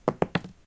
diceThrow2.ogg